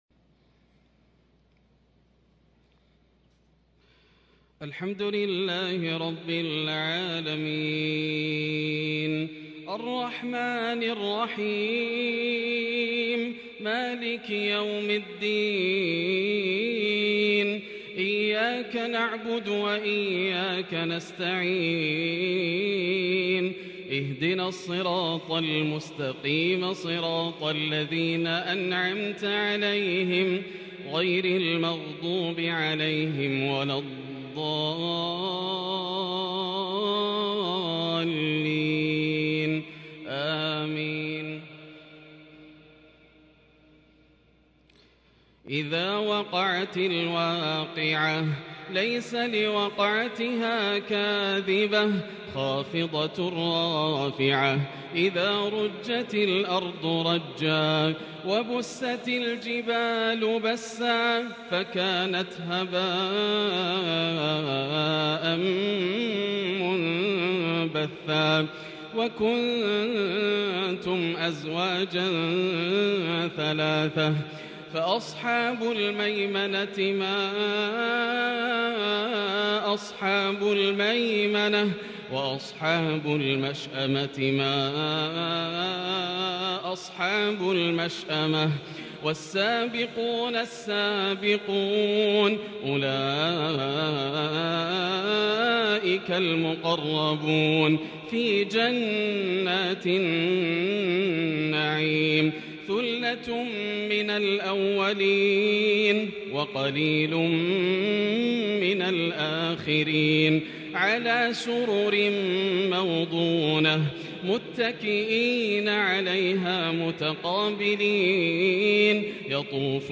عشاء السبت 3-6-1442هـ من سورة الواقعة | Isha Prayer from Surah Al-Waaqia 16/1/2021 > 1442 🕋 > الفروض - تلاوات الحرمين